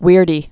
(wîrdē)